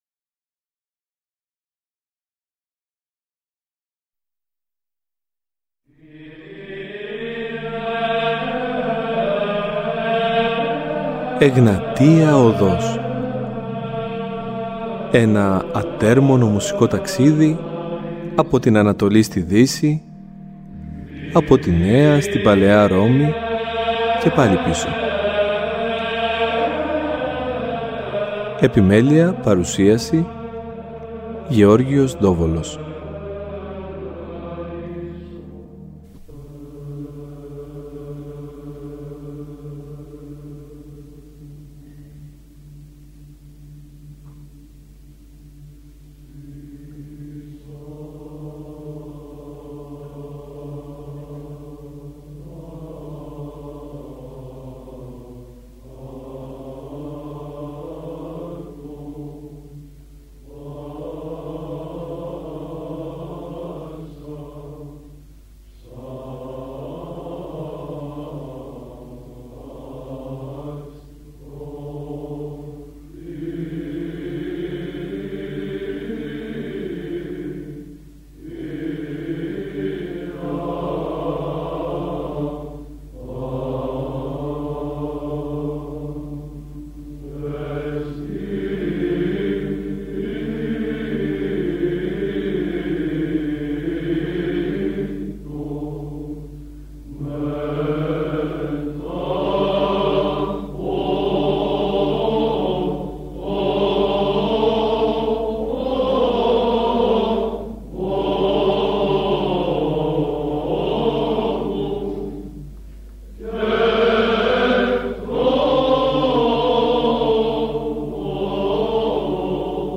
Στα πλαίσια του πολύπτυχου αυτού αφιερώματος, στην εκκλησιαστική μουσική της Θεσσαλονίκης, είχαμε την χαρά να ακούσουμε σπάνια ηχογραφήματα από εμφανίσεις της χορωδίας, αλλά και να μάθουμε ακόμα περισσότερα για το ύφος και την ψαλτική τεχνοτροπία όπως αυτή ενδημεί στην συμπρωτεύουσα. Με επιρροές από Κωσταντινούπολη αλλά και Άγιο Όρος, συνέβαλε σημαντικά στην μεταλαμπάδευση του Βυζαντινού ήχου στον ελλαδικό χώρο, αλλάζοντας άρδην τα ψαλτικά δεδομένα στο β’ μισό του περασμένου αιώνα.